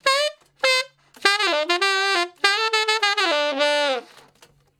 066 Ten Sax Straight (D) 10.wav